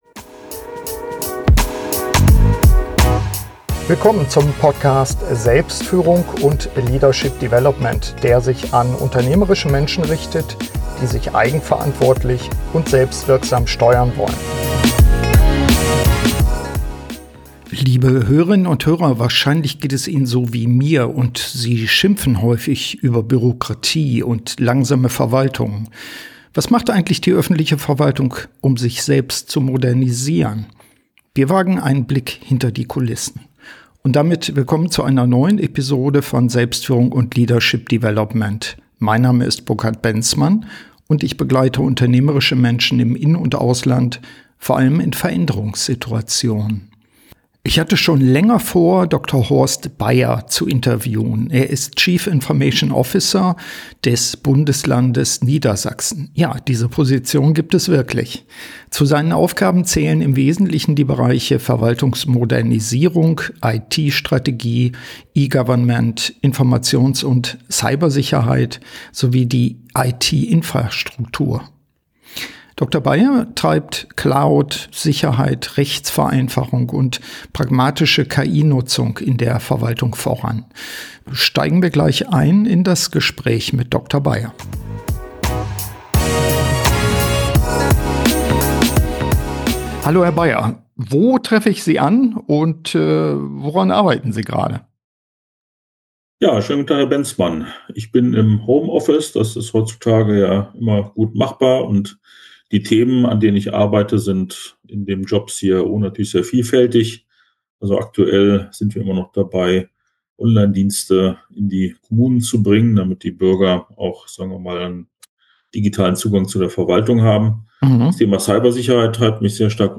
Ist der Einsatz von KI in der öffentlichen Verwaltung hilfreich oder bringt uns das noch mehr Bürokratie und Kontrolle? Ich spreche mit dem CIO von Niedersachen, Dr. Horst Baier, ausführlich über die Ansätze, die Verwaltung zu modernisieren.